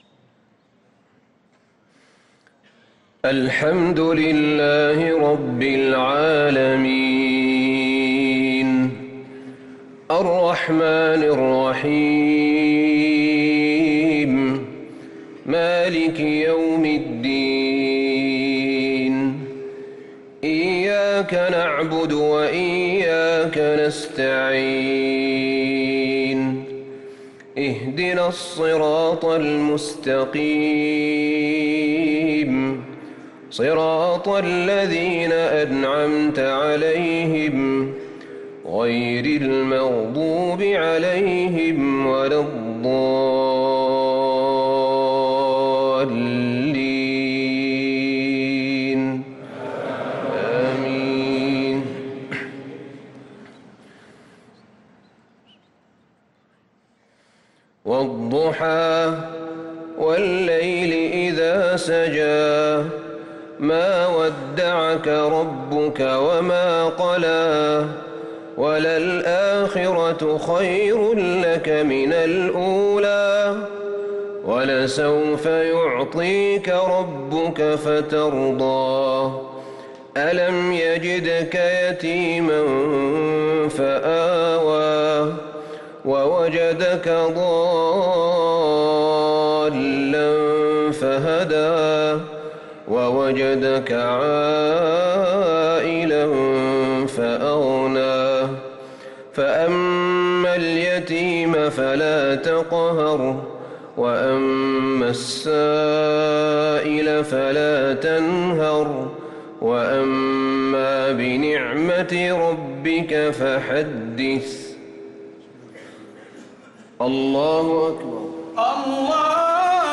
صلاة العشاء للقارئ أحمد بن طالب حميد 27 رمضان 1444 هـ